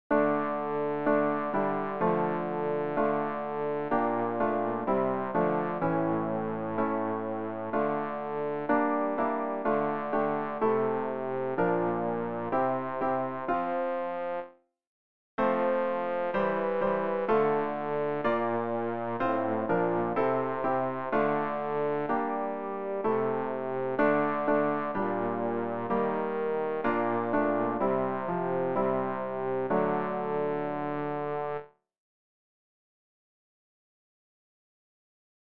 Übehilfen für das Erlernen von Liedern
bass-rg-353-von-guten-maechten-wunderbar-geborgen.mp3